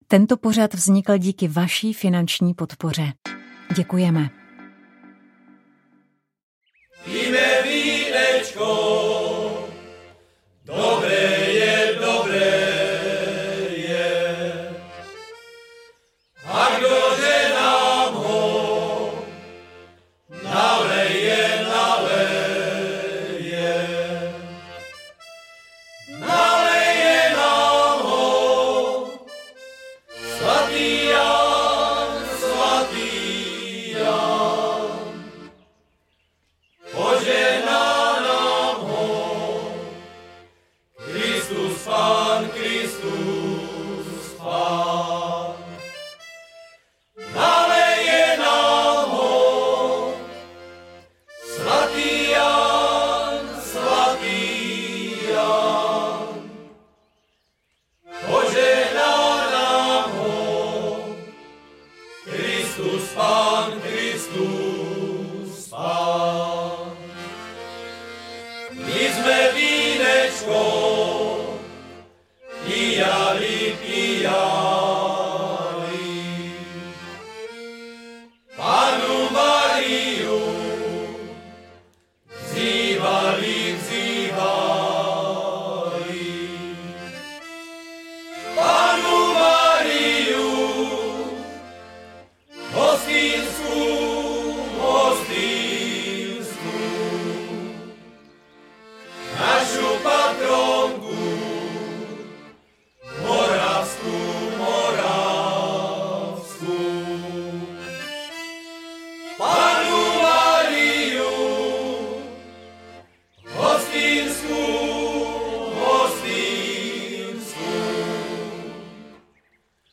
Mnozí k vám promluví i prostřednictvím archivních zvukových záznamů.